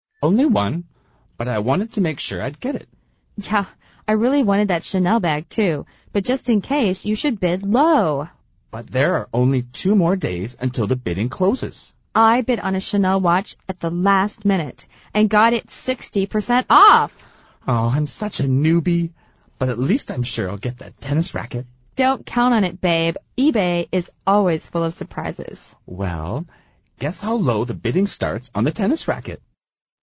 美语会话实录第138期(MP3+文本):Such a newbie 真是个菜鸟